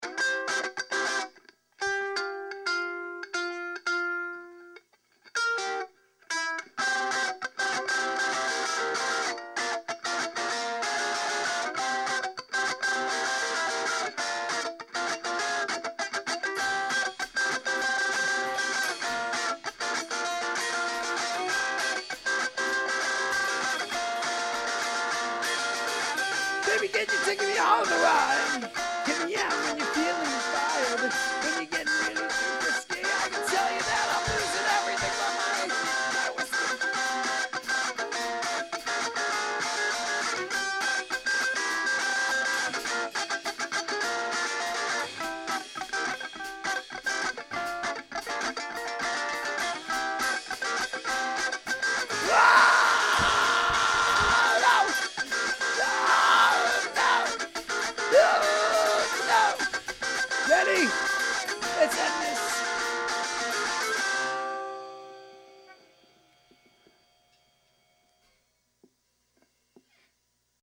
The West Chester Pops on drums